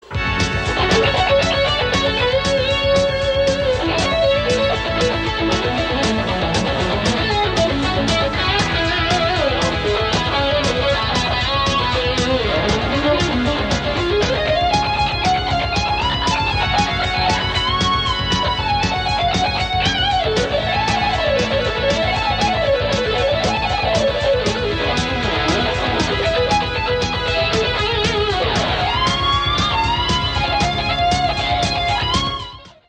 instrumental guitar rock